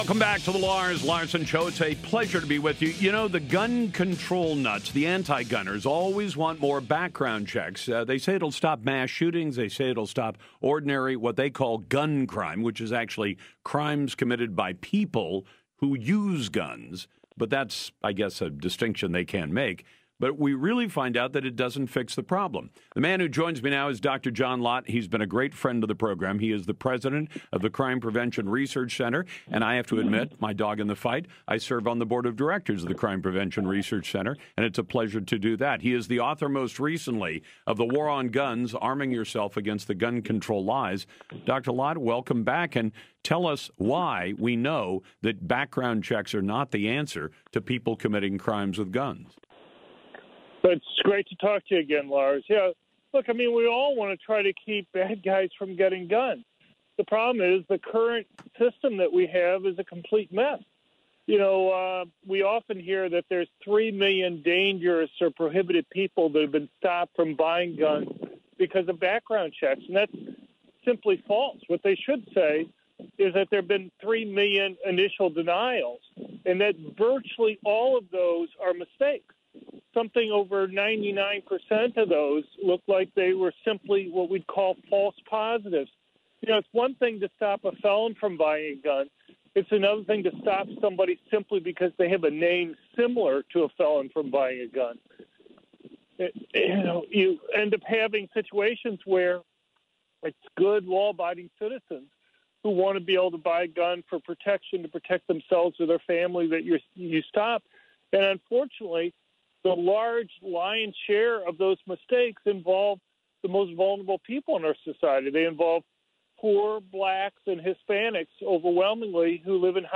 media appearance
Dr. John Lott talked to Lars Larson about his piece in the New York Times and what can be done to fix the background check system.